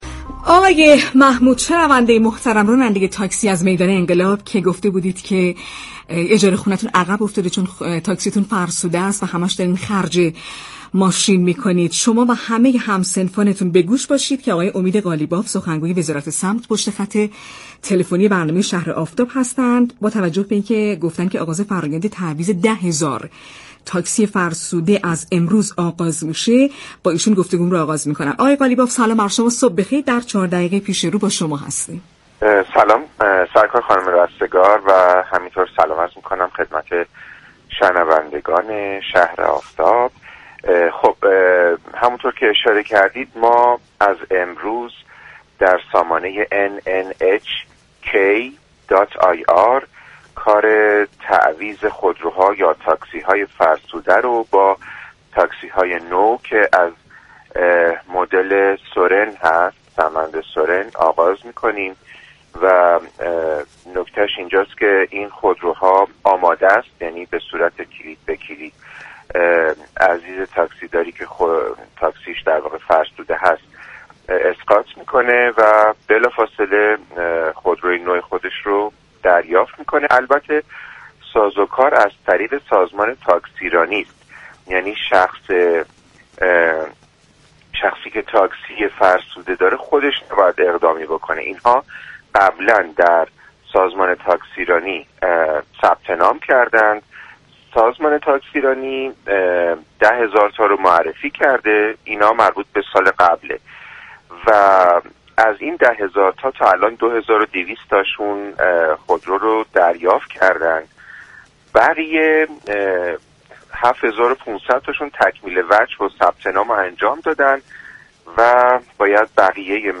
وی در پاسخ به اظهاات یكی از شنوندگان برنامه مبنی براینكه همه درخصوص فروش خودرو صحبت می‌كنند ولی به وضعیت جاده‌ها و خیابانهای شهر و ترافیك توجهی نمی‌كنند؛ گفت: در حوزه گسترش و بهبود وضعیت جاده‌ها و خیابان‌های شهری وزارت راه و شهرداری باید سرمایه‌گذاری‌های جدیدی انجام دهند.